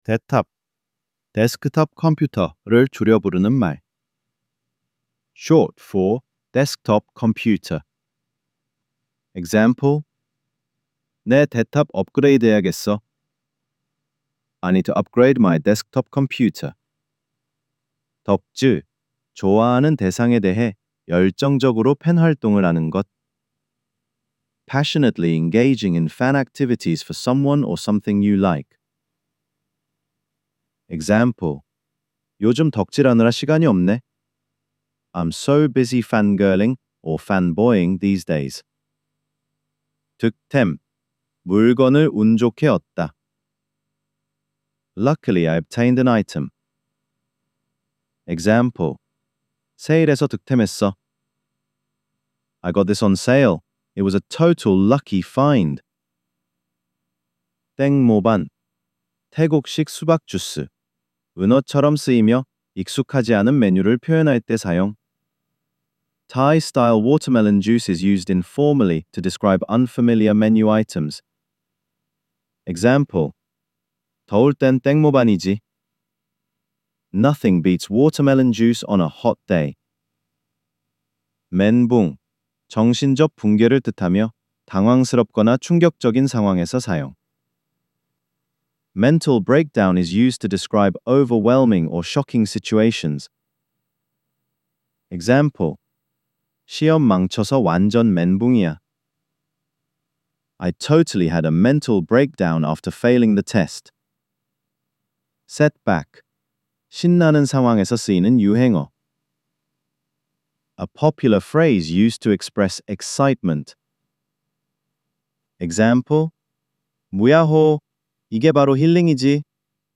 4) Korean Slang Decoded: Vol. 04 – Audio Narration